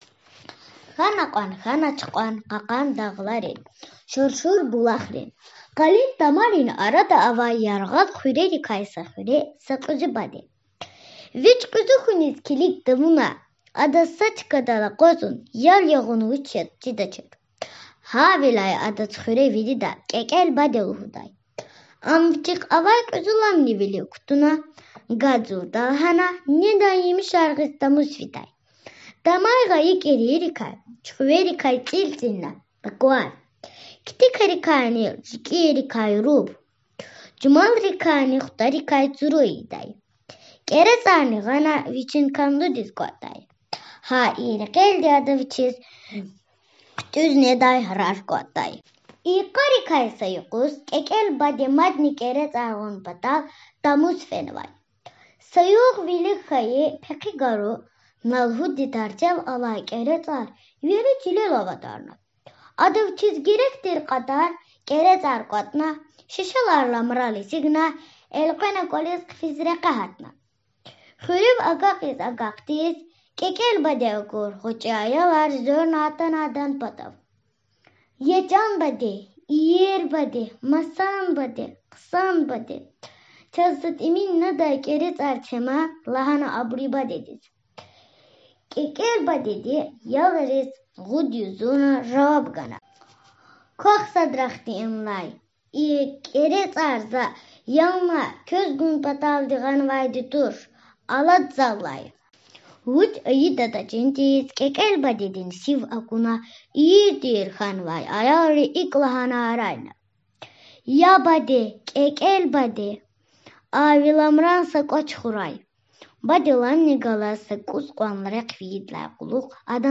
КIекIел баде (аудио мах)